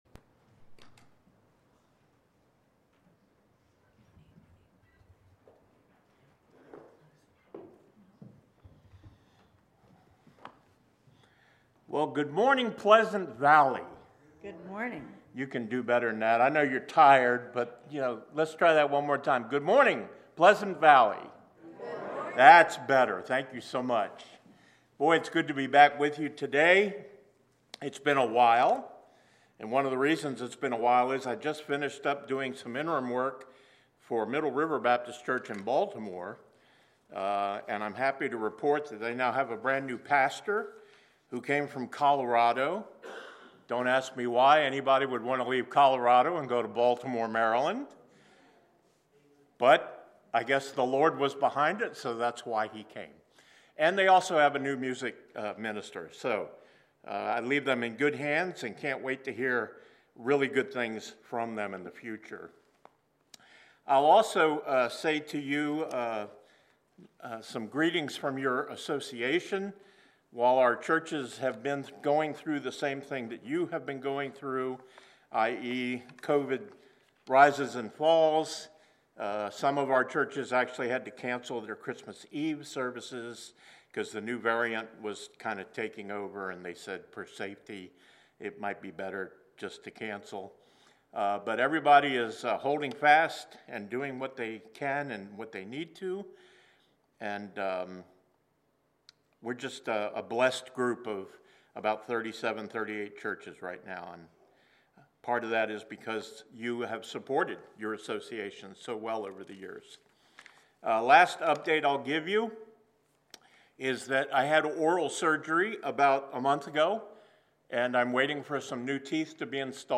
Series: Guest Speaker
Isaiah 58.1-12 Service Type: Sunday Worship Service Download Files Bulletin « Would You Like to Speak with God?